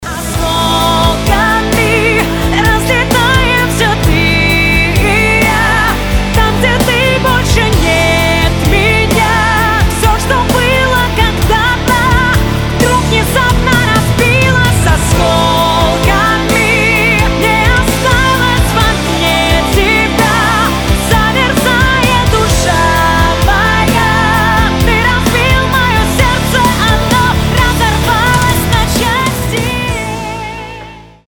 • Качество: 320, Stereo
поп
громкие
женский вокал
грустные
печальные